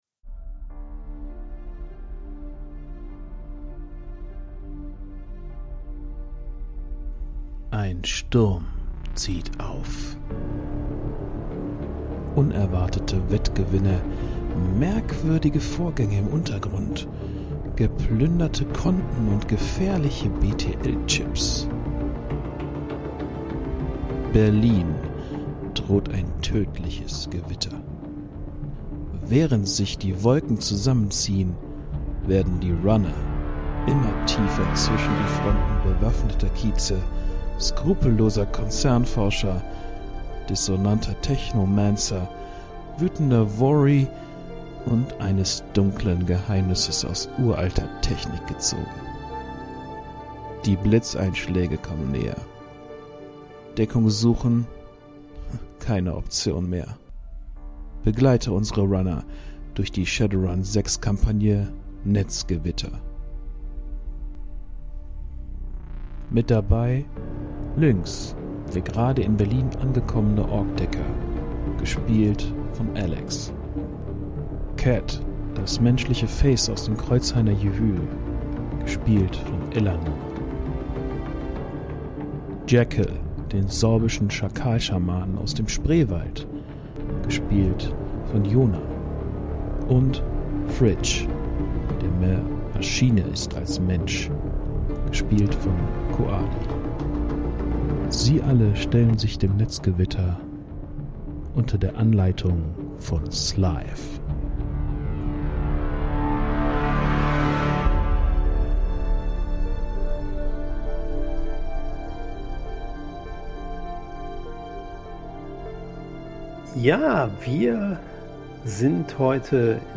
Dies ist ein Mitschnitt einer Pen and Paper Rollenspielrunde der Shadowrun 6 Kampagne Netzgewitter.▬ Worum geht´s?